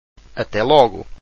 Até logo   Atay logu – lit. ‘until straight away’.